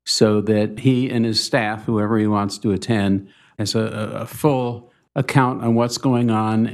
Mayor Pro Tempore Jim Pearson proposed they get together with City Manager Pat McGinnis to document their complaints to see what can be done.